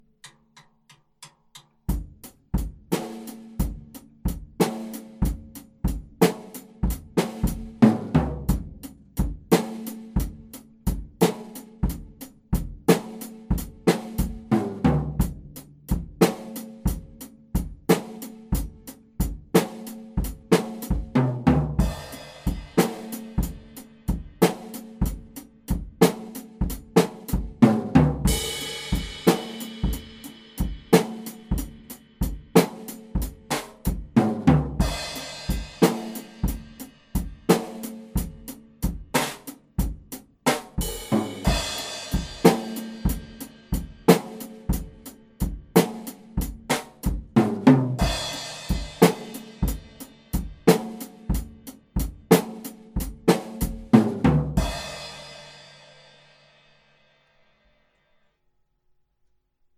Schlagzeug
Erfindung während einer Stunde.
Ich wechsel die Instrumente im vierten Takt und setzte ab und zu ein Becken auf die Eins, das reicht schon, um aus der Idee Musik zu machen.